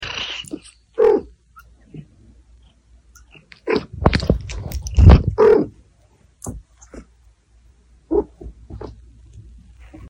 When mommy cat calls ❤ sound effects free download
Abyssinian kittens listening to mother cat